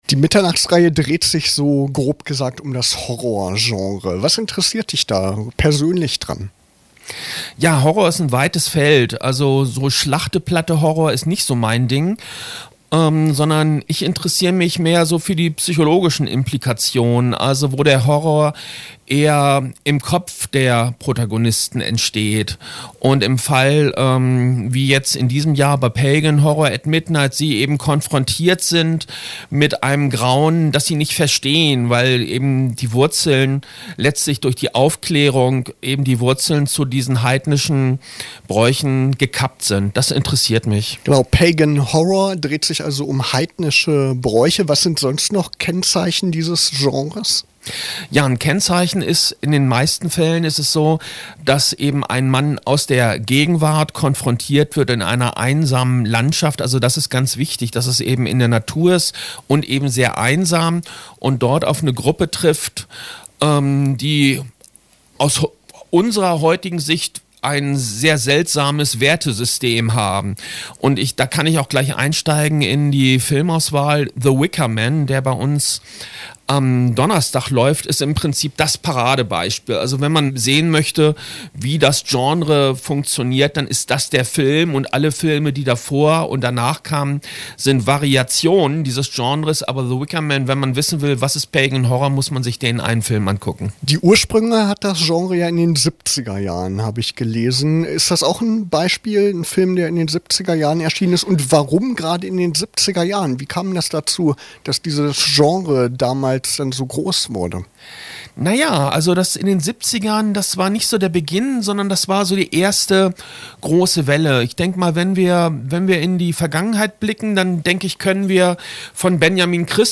Interview: „Pagan Horror at Midnight“ beim 32. Internationalen Filmfestival Braunschweig